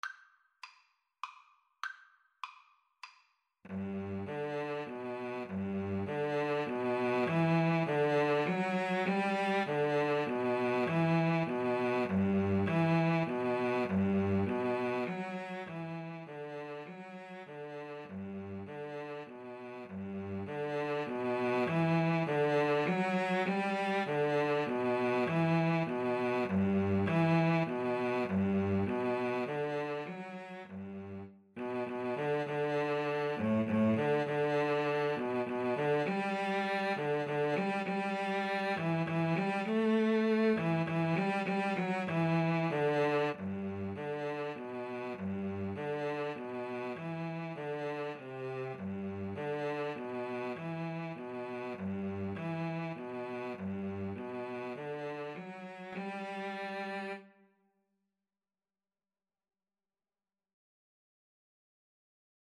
Moderato
Classical (View more Classical Violin-Cello Duet Music)